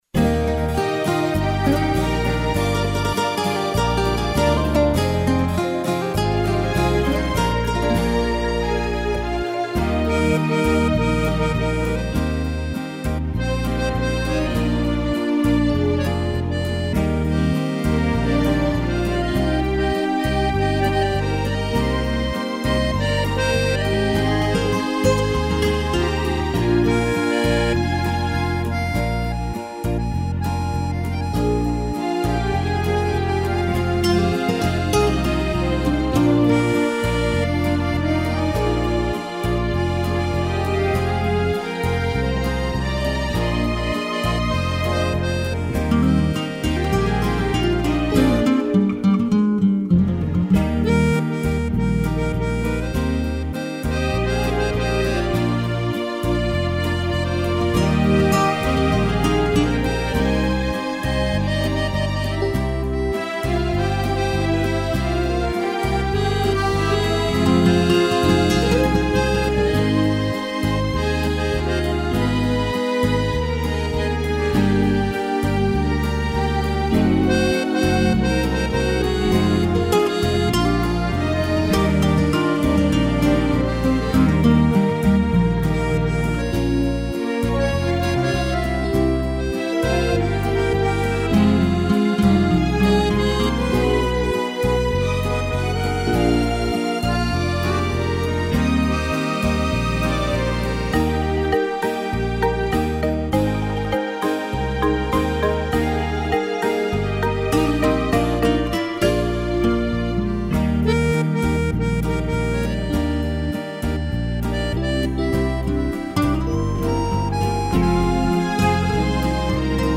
violão
strings
(sem bateria)